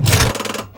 controlStick3.wav